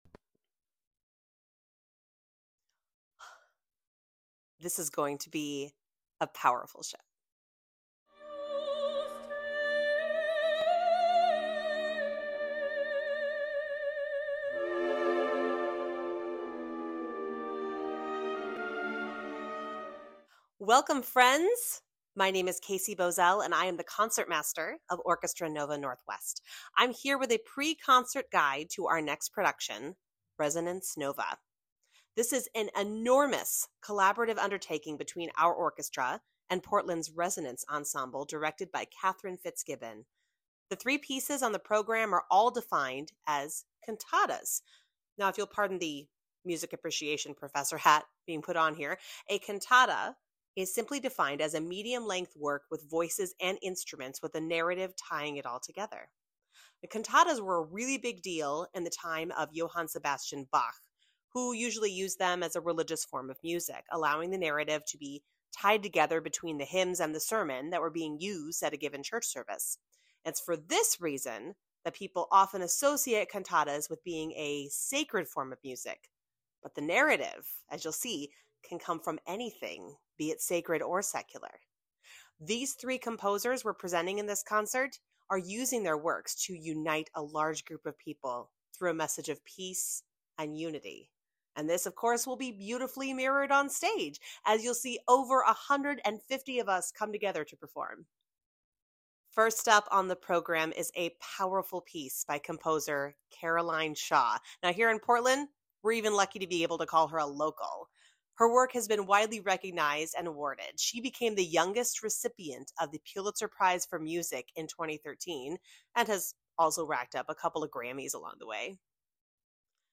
Resonance Nova Pre-Concert Talk | Orchestra Nova Northwest